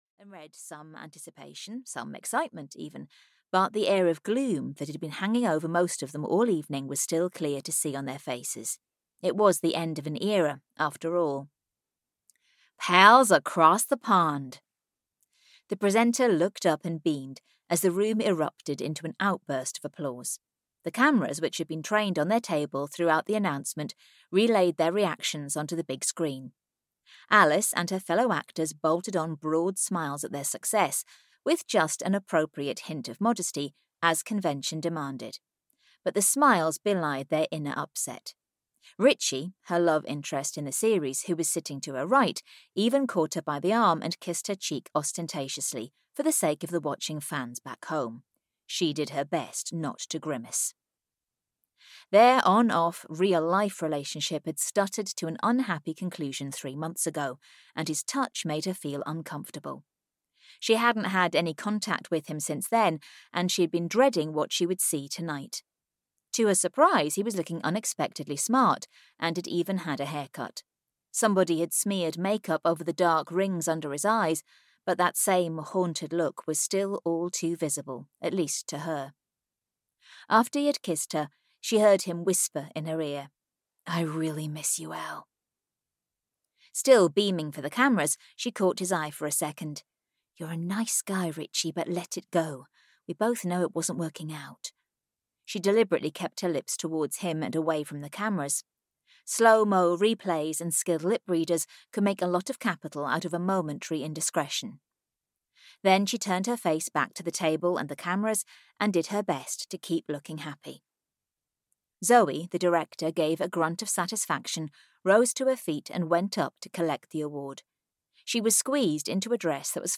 Audio knihaSecond Chances in Chianti (EN)
Ukázka z knihy